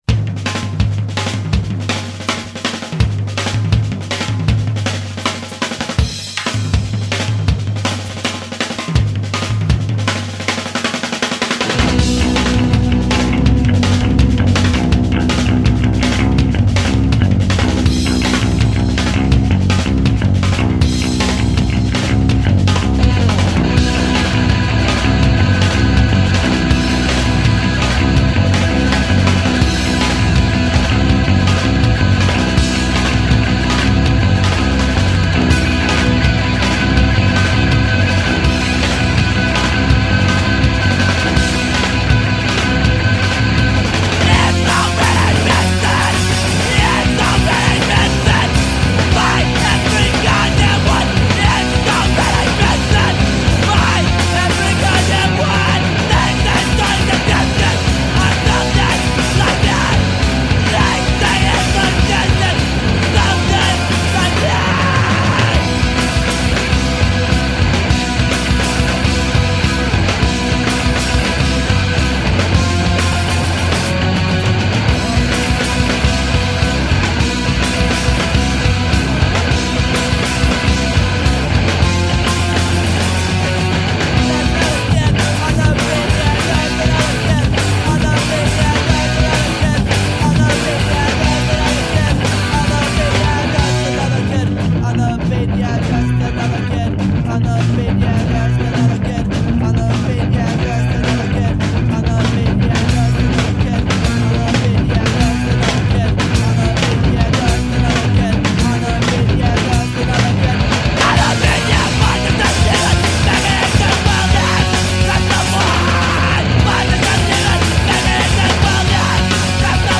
ХАОТИЧЕСКОЕ ЭМО